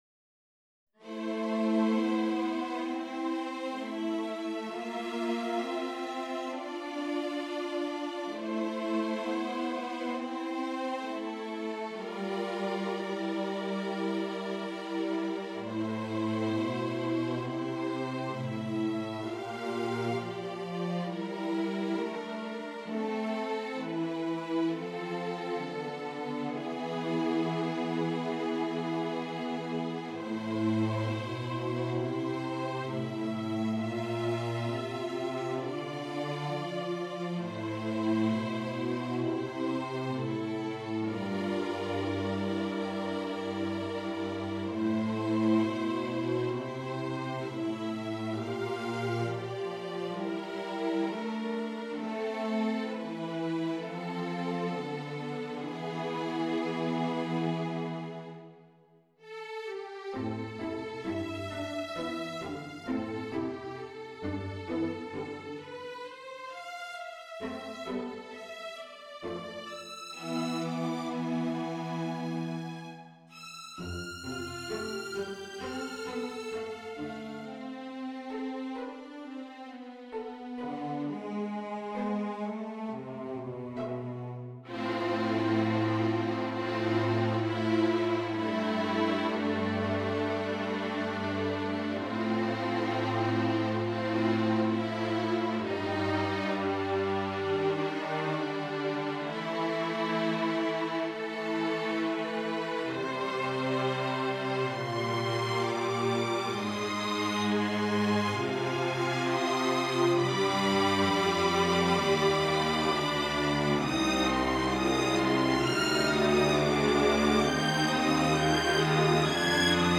Strings